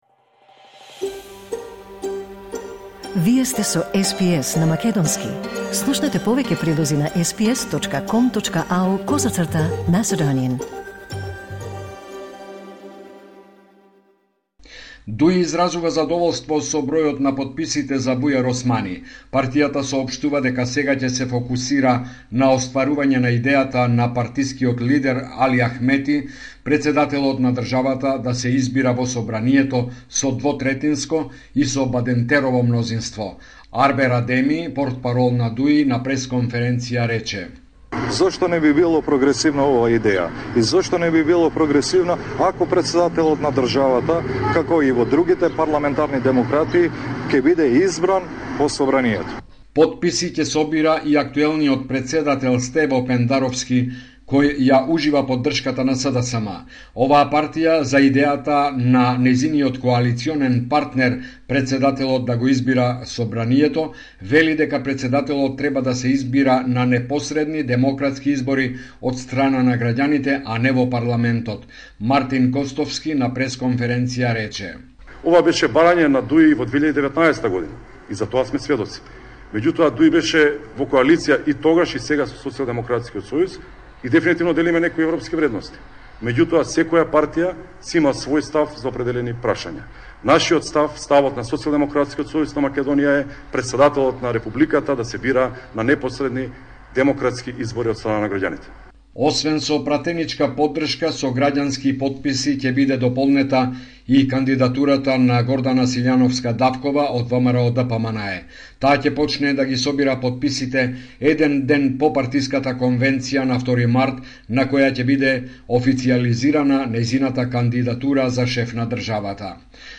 Homeland Report in Macedonian 28 February 2024